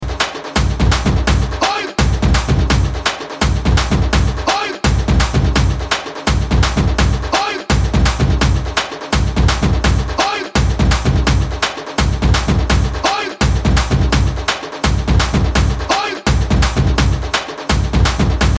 CategoryAll Types Of Dj Beats